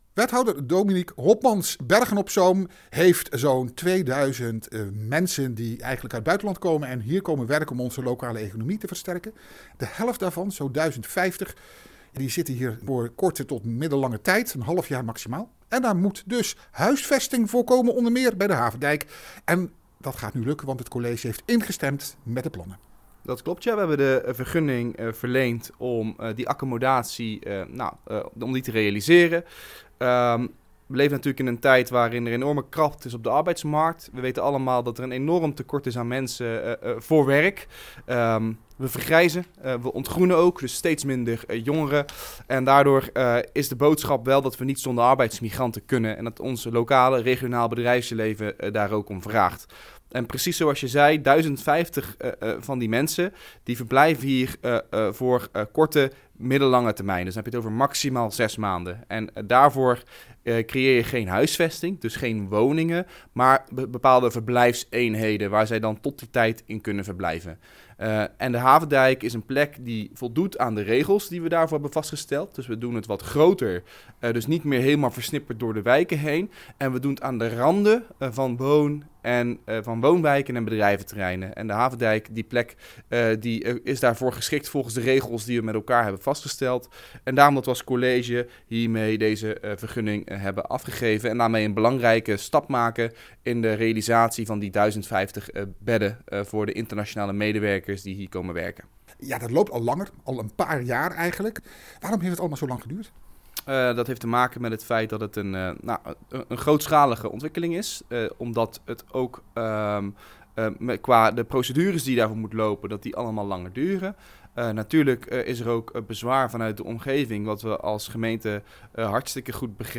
Audio: Het Bergse college heeft ontwikkelaar Cobevo de vergunning verleend voor de bouw van het migrantenonderkomen aan de Havendijk – een gesprek met wethouder Hopmans.